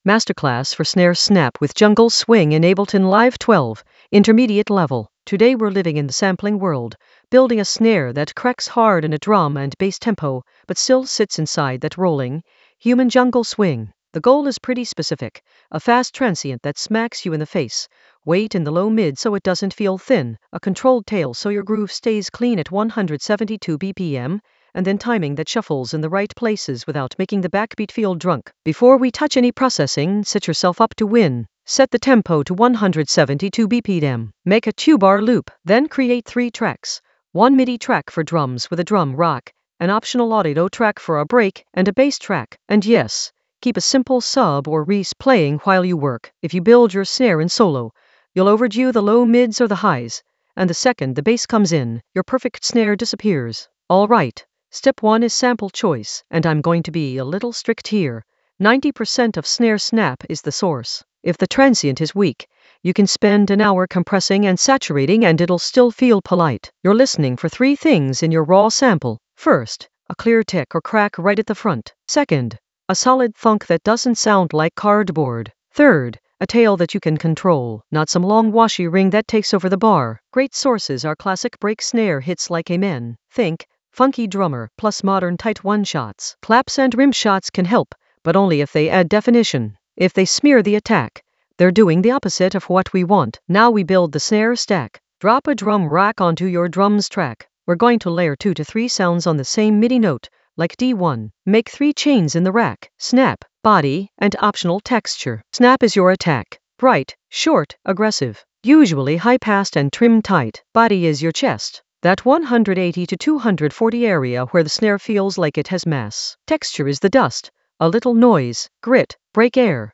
Narrated lesson audio
The voice track includes the tutorial plus extra teacher commentary.
An AI-generated intermediate Ableton lesson focused on Masterclass for snare snap with jungle swing in Ableton Live 12 in the Sampling area of drum and bass production.